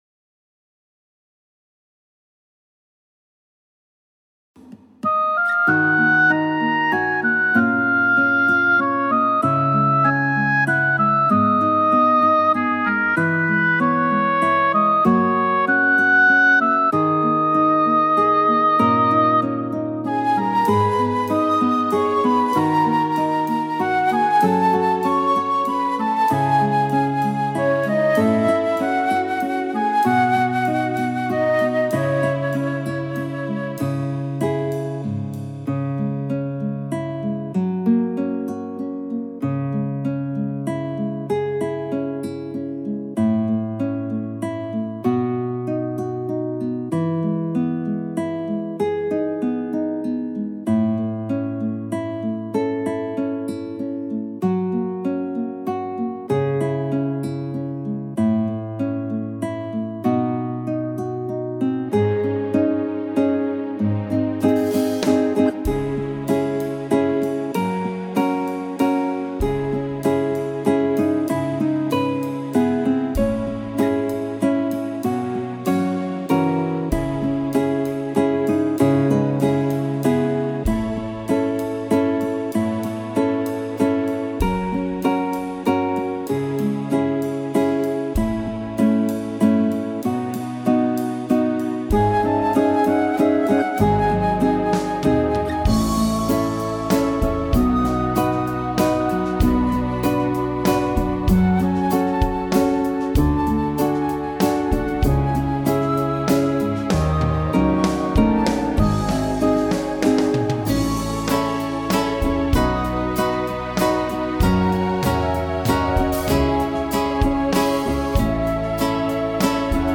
•   Beat  01.
(C#m)